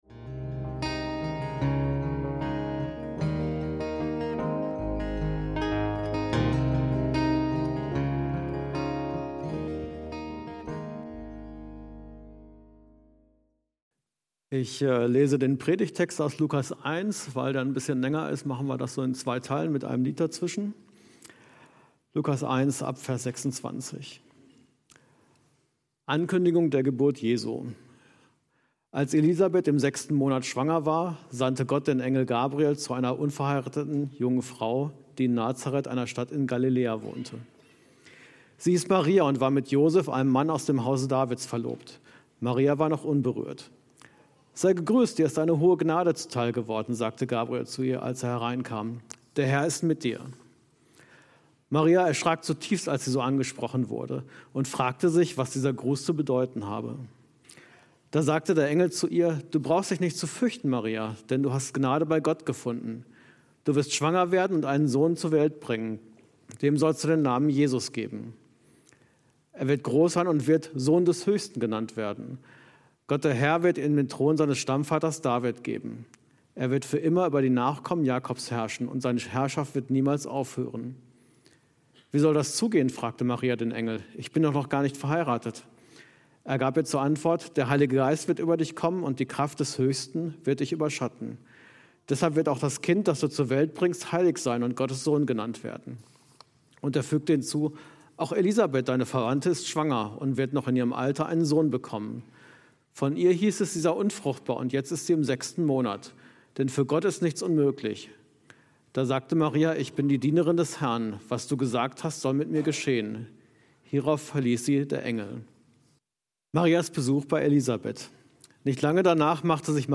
Gott kommt zu Maria und uns - Predigt vom 08.12.2024